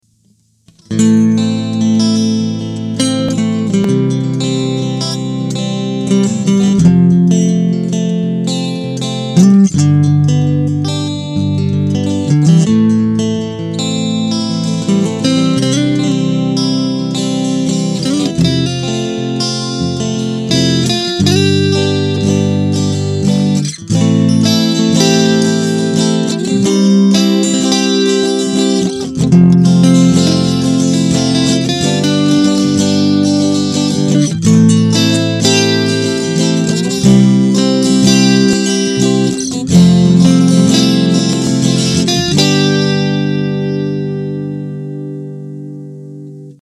דמו